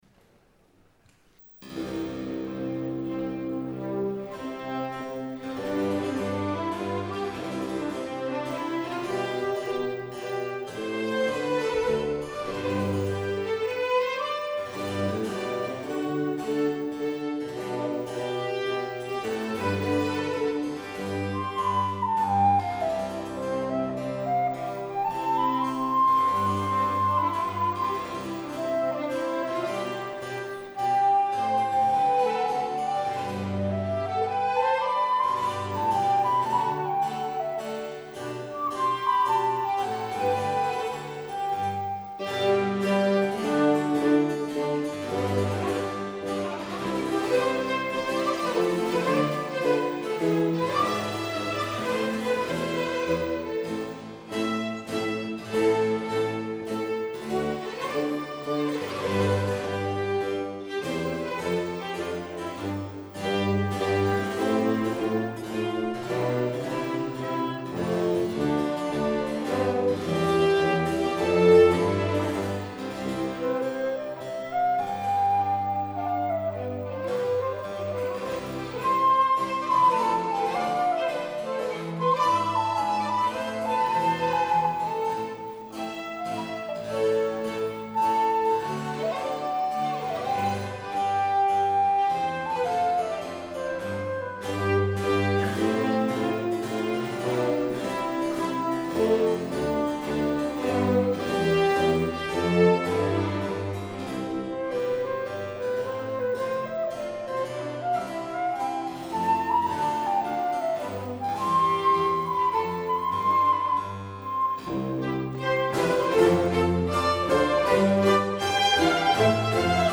Les Eléments – Ensemble Baroque du Léman